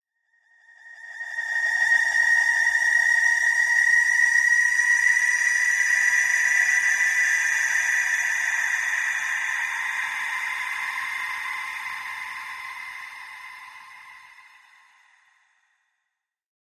Ambiance atmosphere call to sirens fantasy atmo